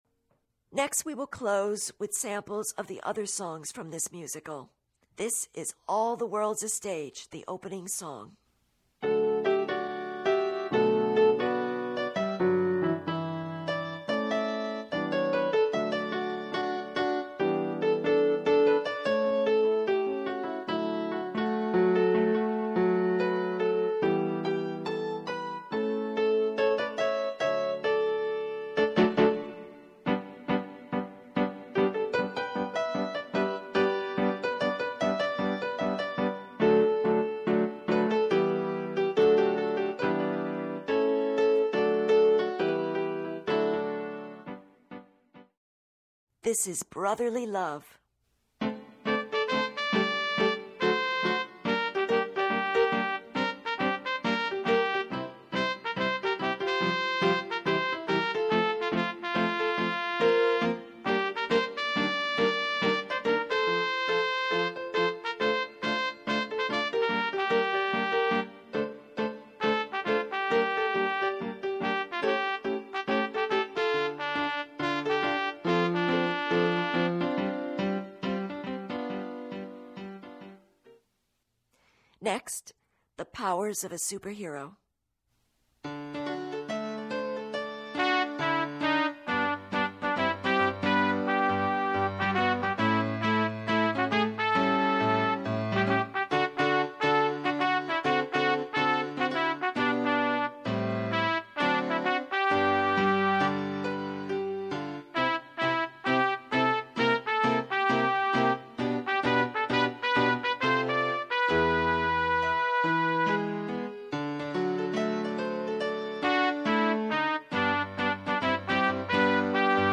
short instrumental samples of the other songs
as recorded in Inwood Hill Park Studio